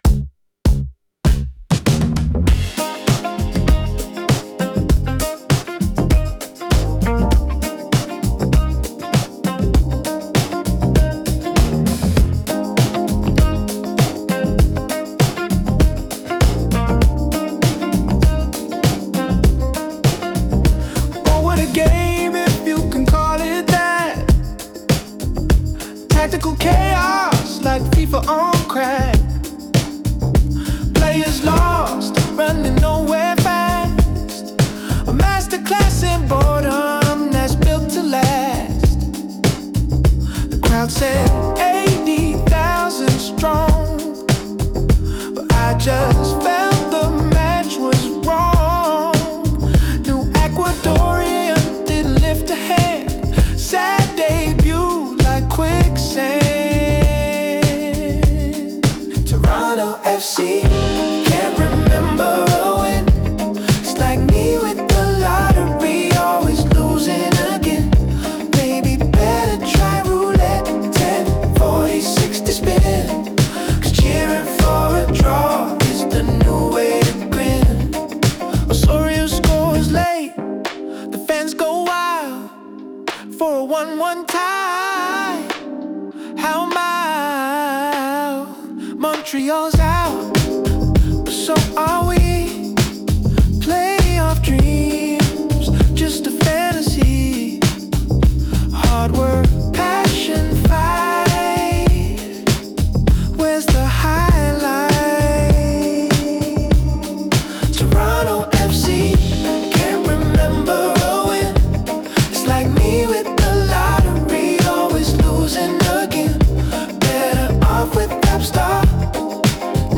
En medio de una temporada llena de contrastes, decidí transformar la frustración y la ironía de esos partidos en algo más grande: una serie de canciones que combinan fútbol, política y groove.